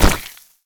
poly_shoot_lava.wav